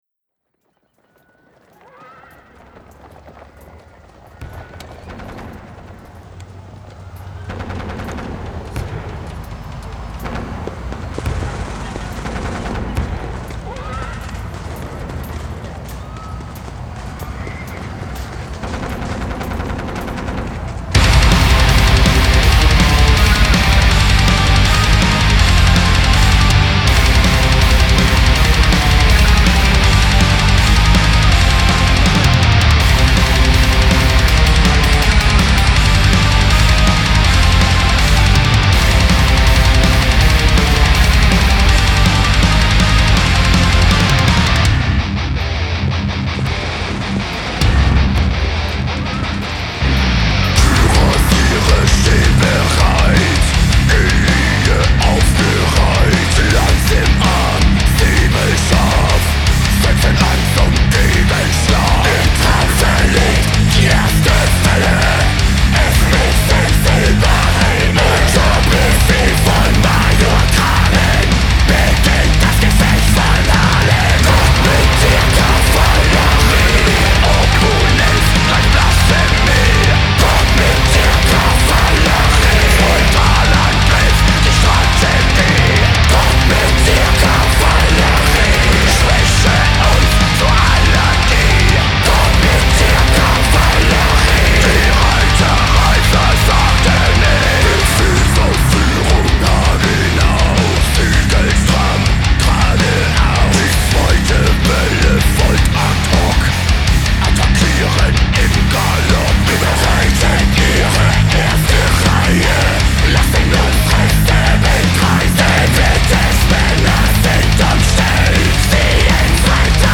Жанр: Black Metal